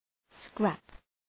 Προφορά
{skræp}